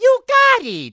Mario tells the player they got it. From Mario Golf: Toadstool Tour.